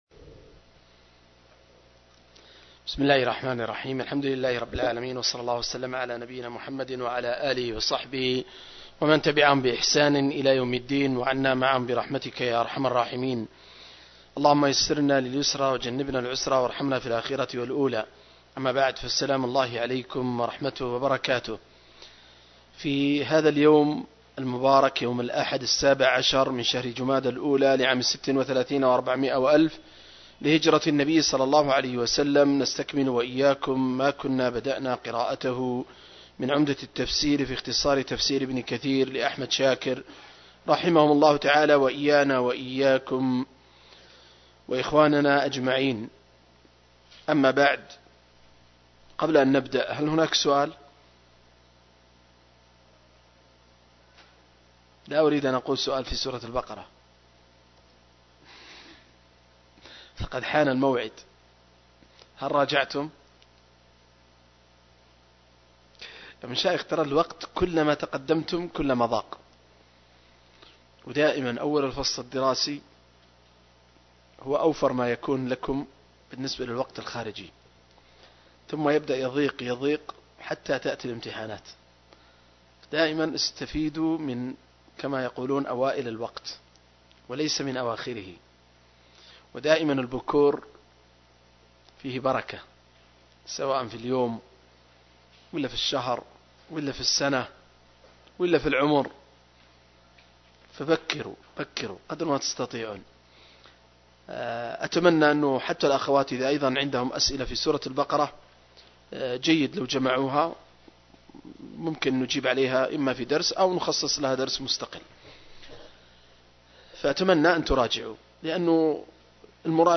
071- عمدة التفسير عن الحافظ ابن كثير رحمه الله للعلامة أحمد شاكر رحمه الله – قراءة وتعليق –